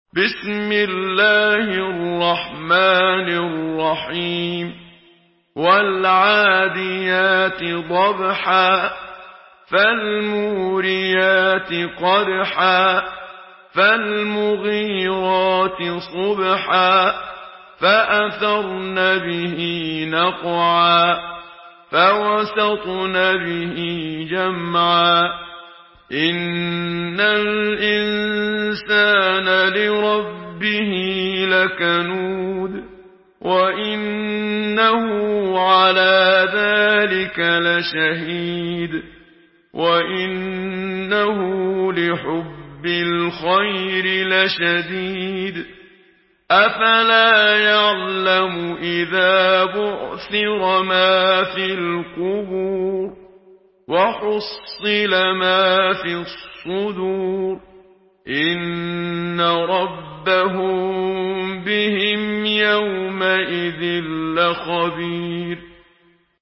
Surah العاديات MP3 by محمد صديق المنشاوي in حفص عن عاصم narration.
مرتل